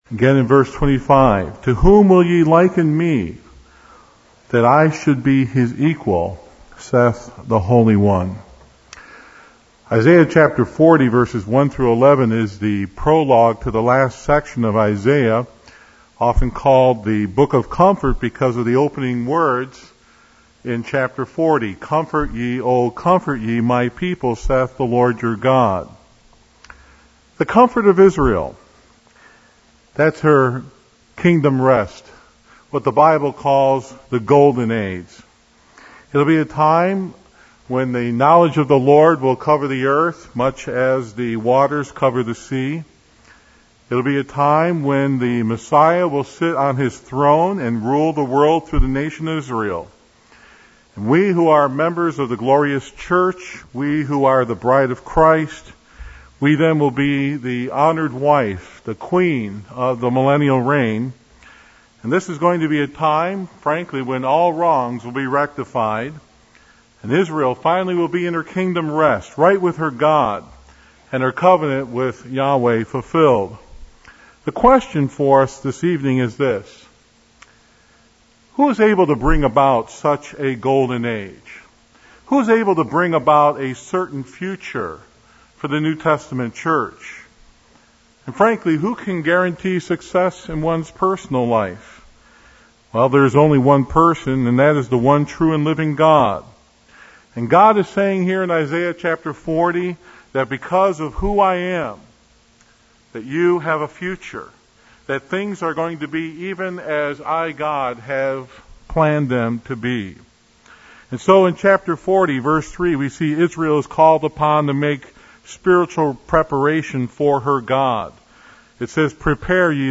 2013 Our Incomparable God Preacher